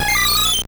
Cri de Zarbi dans Pokémon Or et Argent.